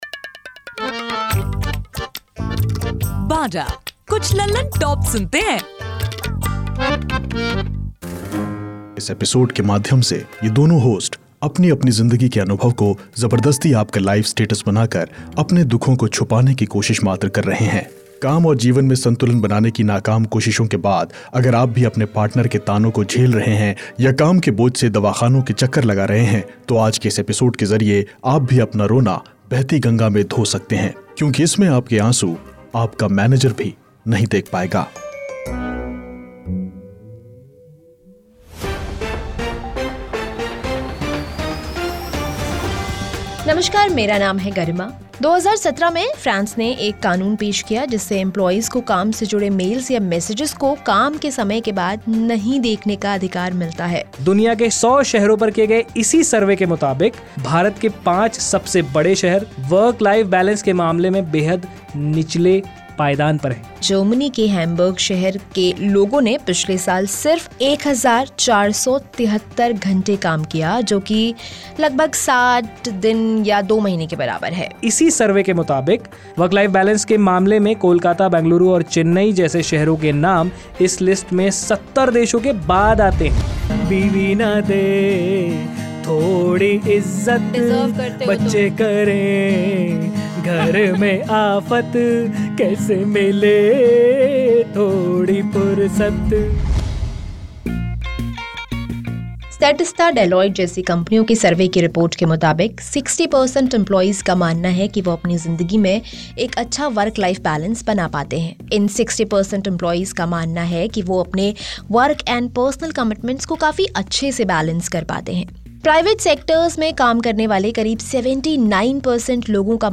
साथ ही एपिसोड के एन्ड में वर्क लाइफ बैलेंस पर एक गुदगुदाती पैरोडी को भी सुनने को मिलेगा.